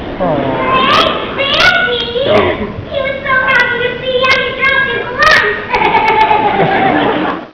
Live Sound Clips from "Grease'